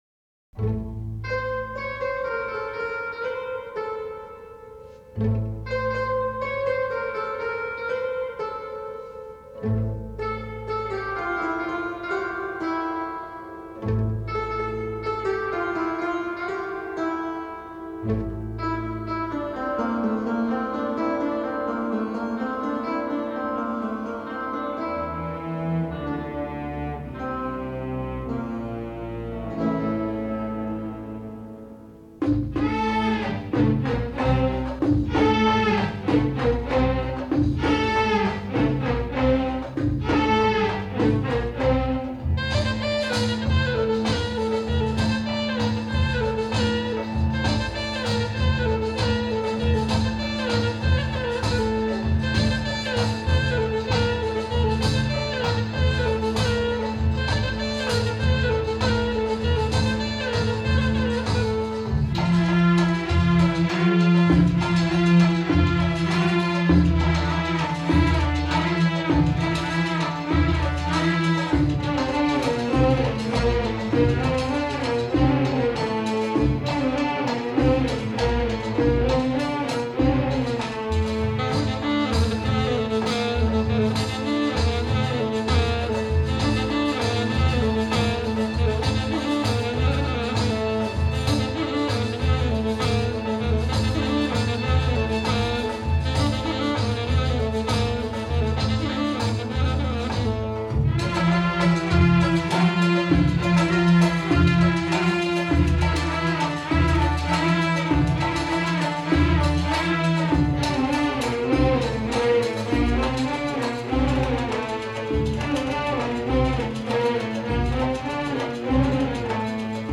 ترانه عربی اغنية عربية